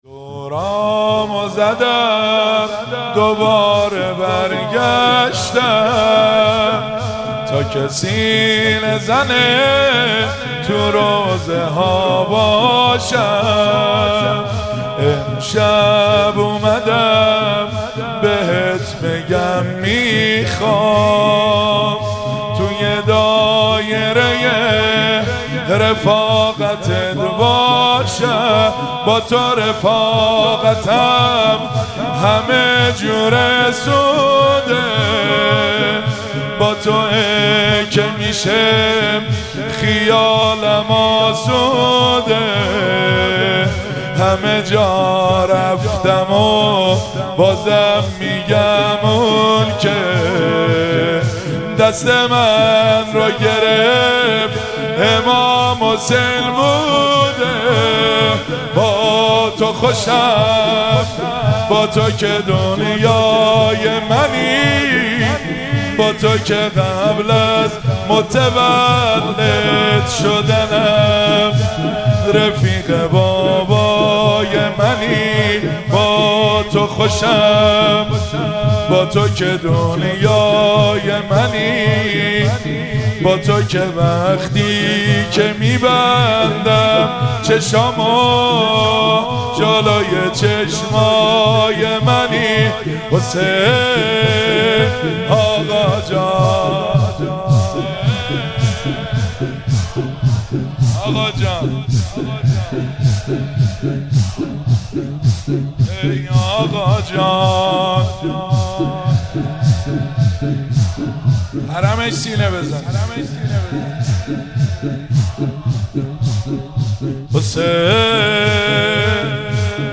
زمینه- دورامو زدم دوباره برگشتم